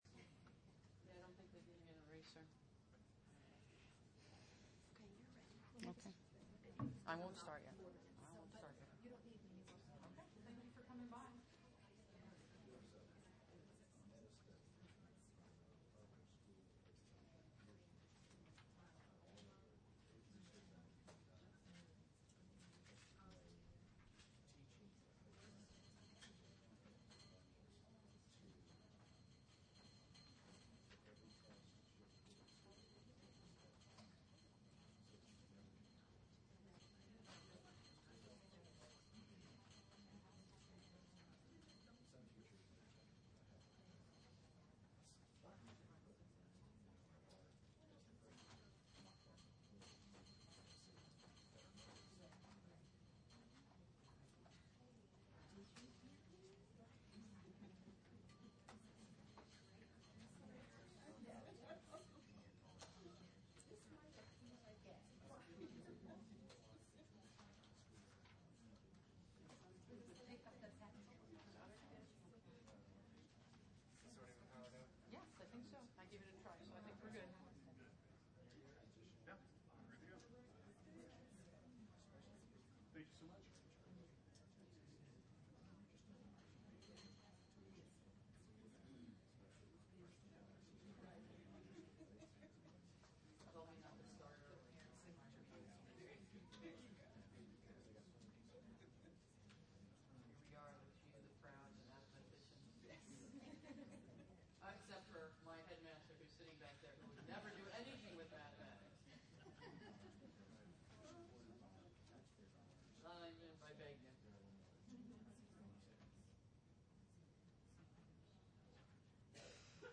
2008 Workshop Talk | 1:01:35 | All Grade Levels, Math
The Association of Classical & Christian Schools presents Repairing the Ruins, the ACCS annual conference, copyright ACCS.